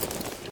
tac_gear_37.ogg